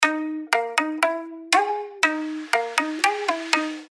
• Качество: 320, Stereo
без слов
биты